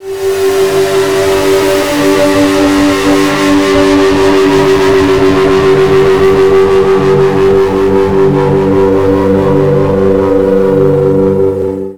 03 Feed Beck 160 D.wav